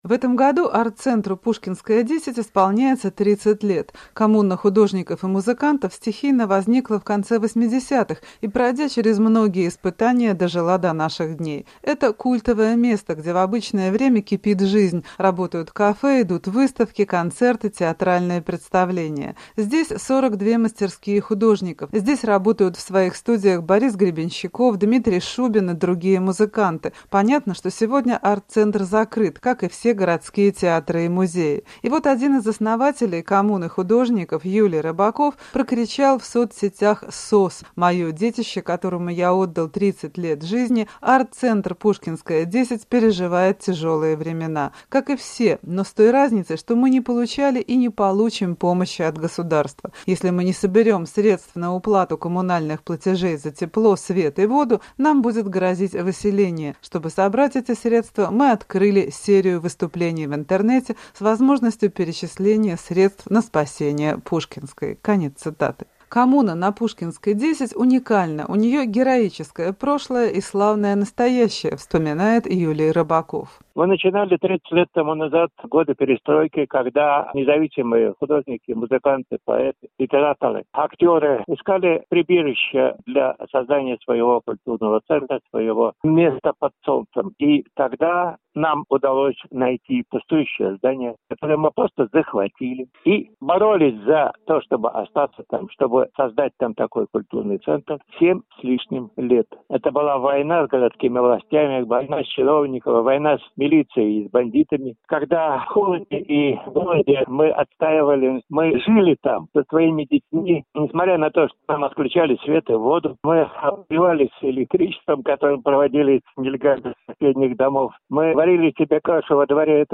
Говорят обитатели "Пушкинской-10"